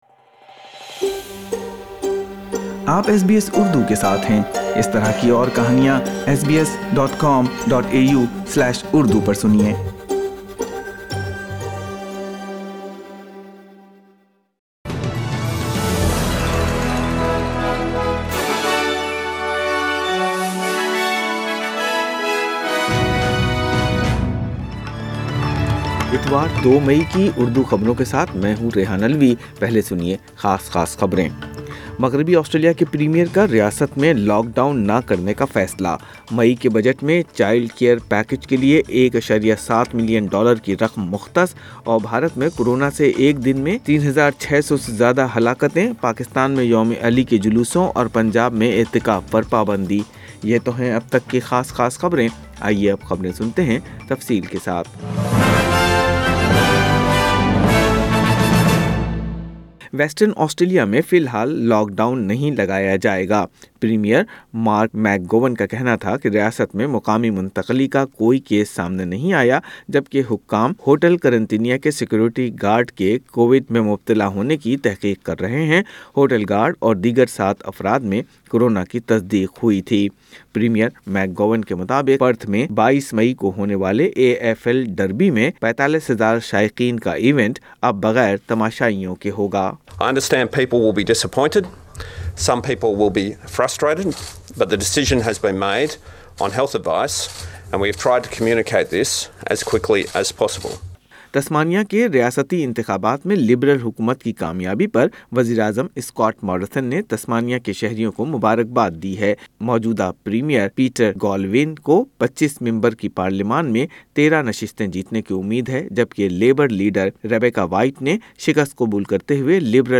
In this bulletin, Western Australia avoids another lockdown, The May Budget to include a $1.7 billion childcare package to get more parents into full-time work, The federal government's ban on its own citizens returning from India is criticized.